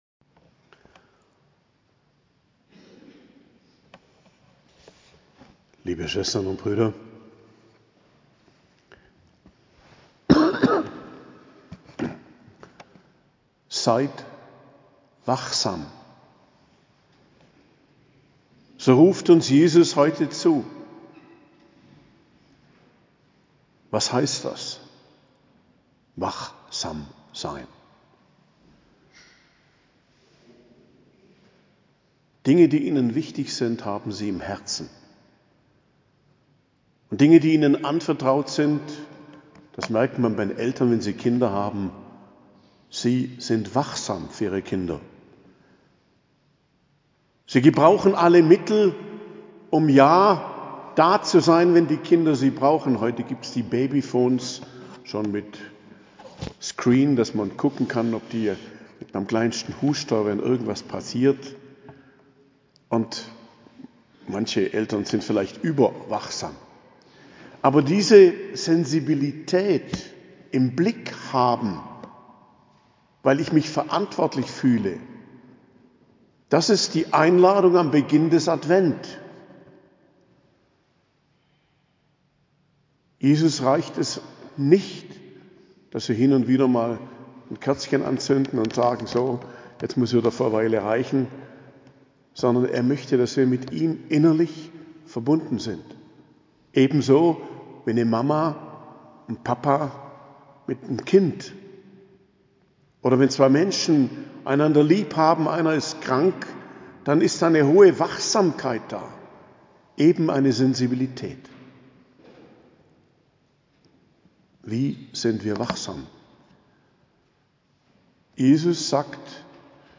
Predigt zum Ersten Adventssonntag, 3.12.2023